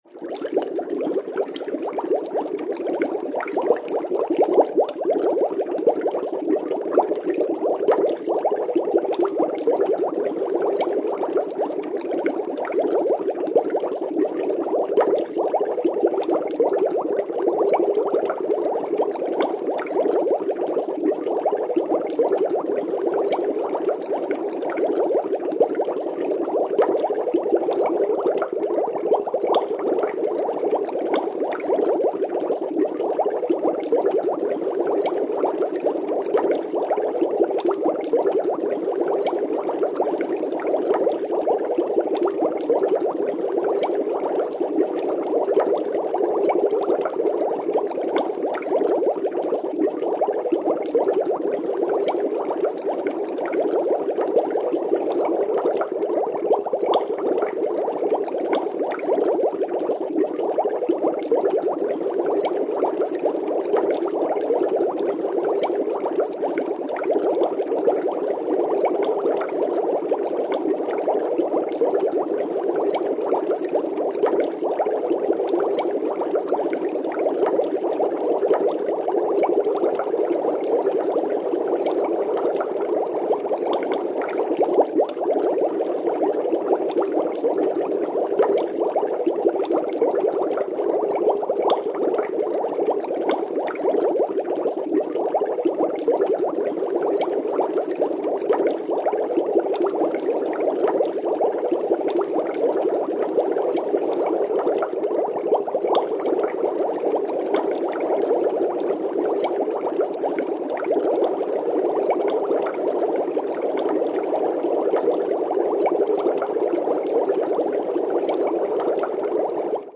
Шум пузырьков в аквариуме